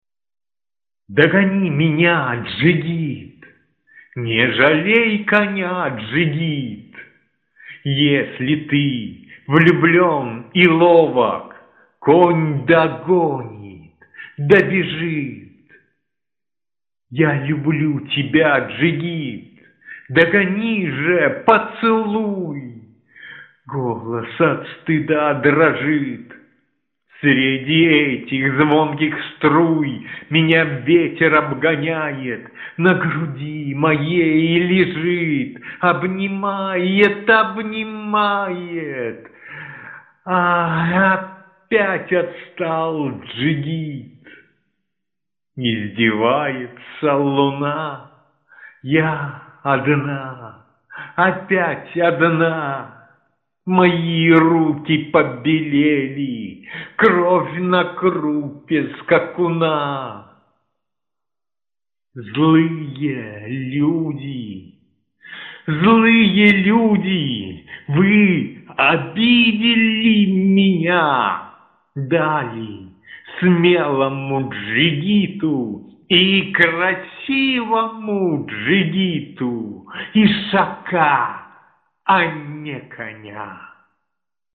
звучащие стихи